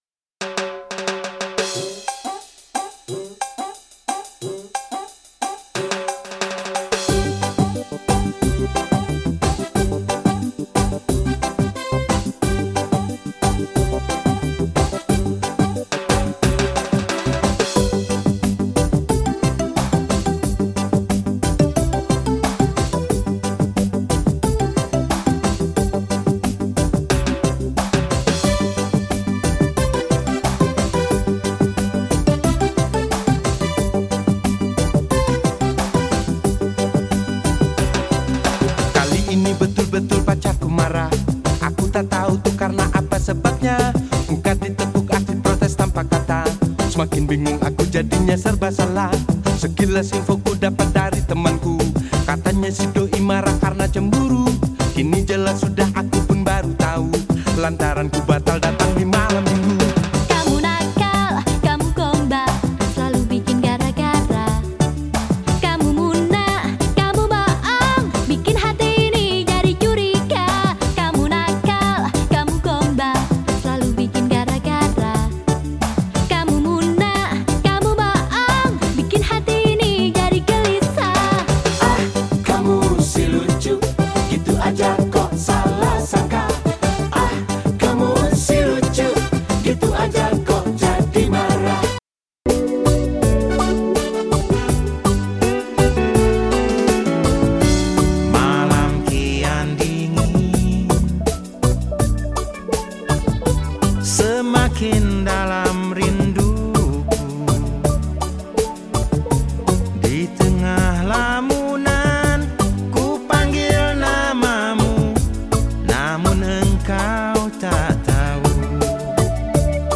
当前位置 > 首页 >音乐 >CD >世界音乐 >雷鬼
※試聴は音質を落しています。実際の音質はもっと良いです。